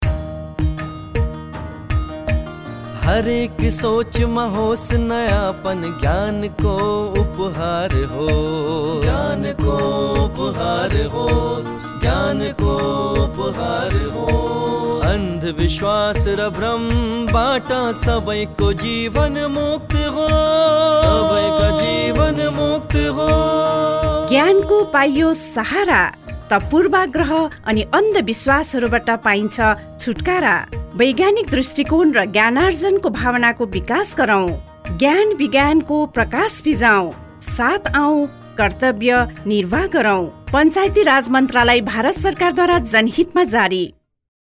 Radio Jingle